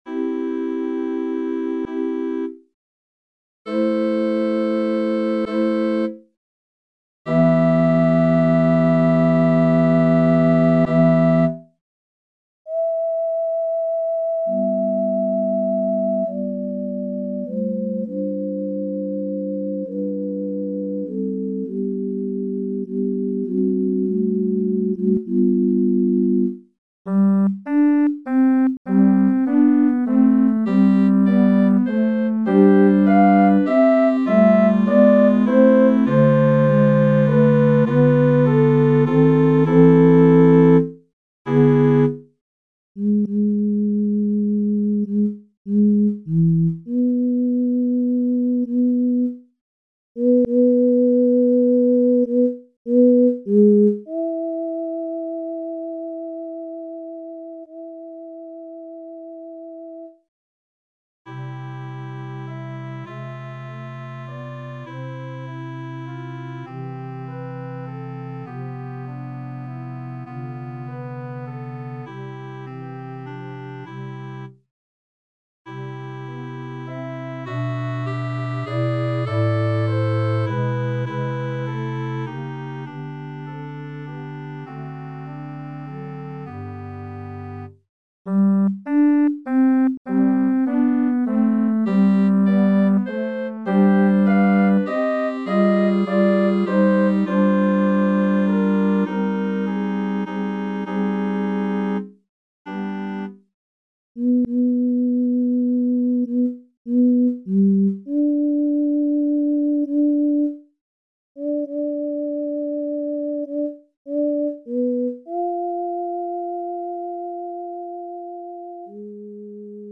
MP3 (3,4 Mo) S A T B